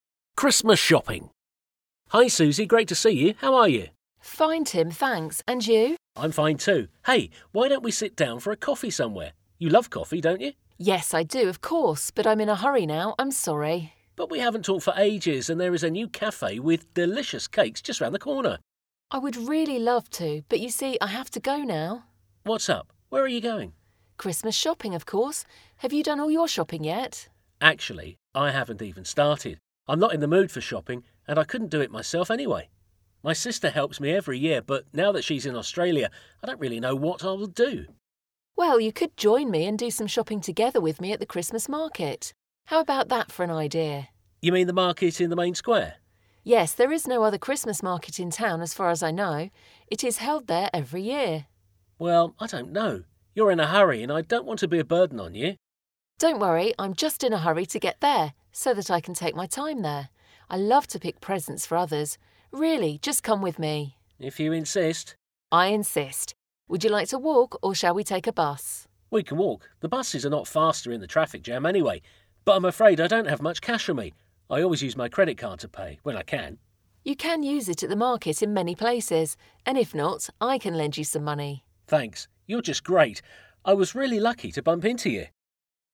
5_Christmas_Shopping_Dialogue1.mp3